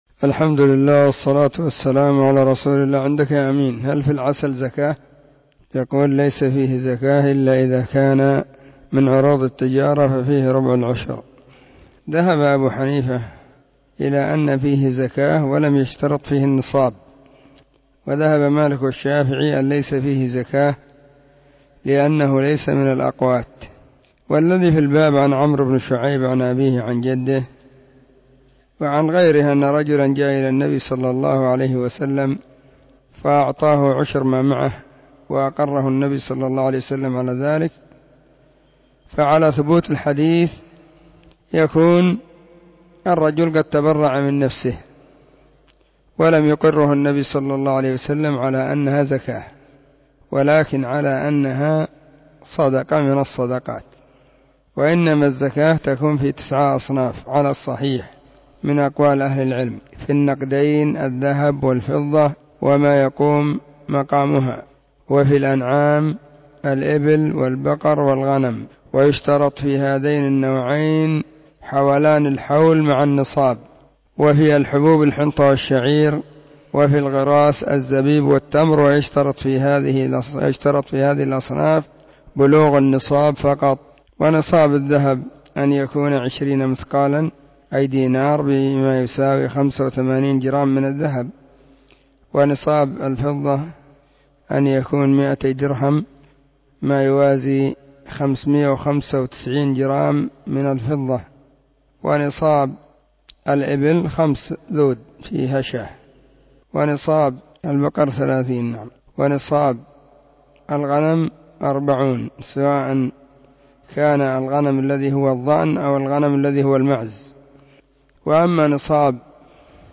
📢 مسجد الصحابة – بالغيضة – المهرة، اليمن حرسها الله.